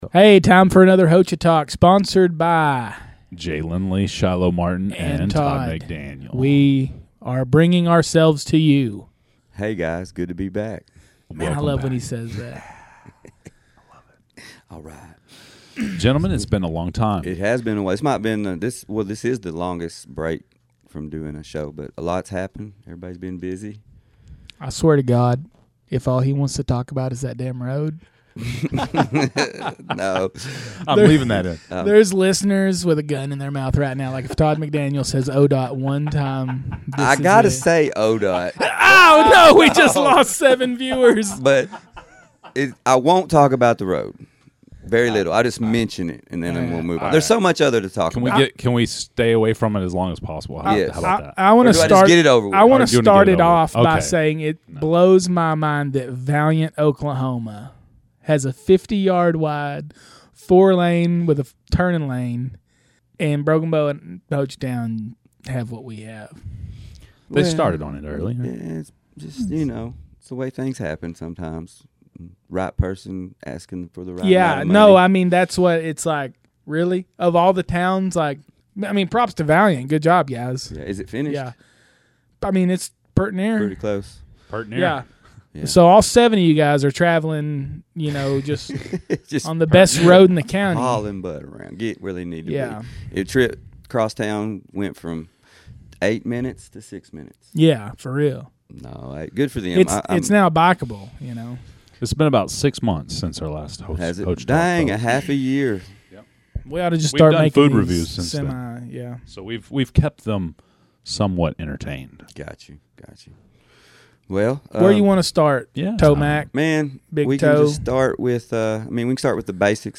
In the 6th and final episode from Water Day at the state capitol, we have the house Majority Floor Leader Jon Echols, and he gets straight into the House Transportation Bill that affects ODOT, and the future of Hochatown traffic.